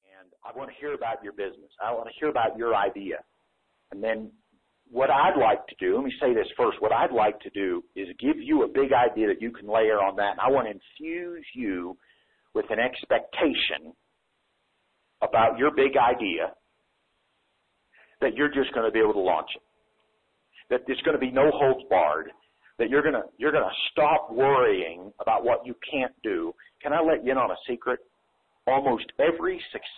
This is an audio course with over 1.5 hours of very helpful lessons all about the most innovative ideas.